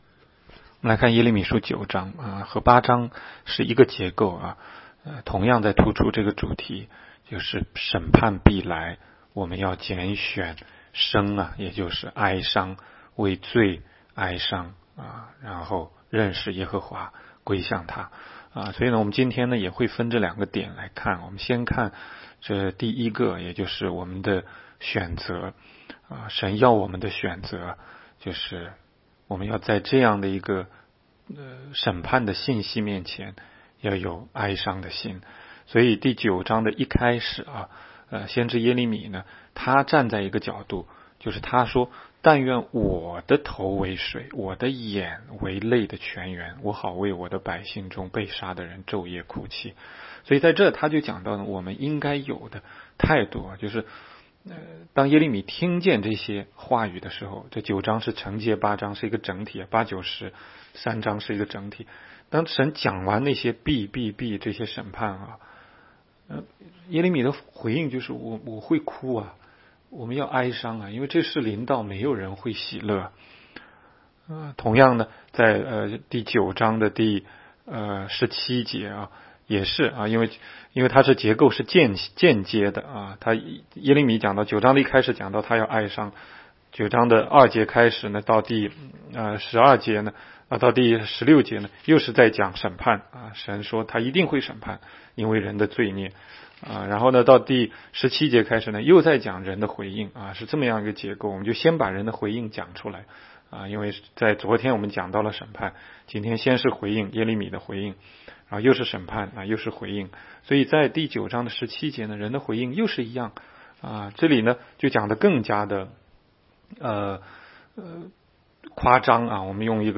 每日读经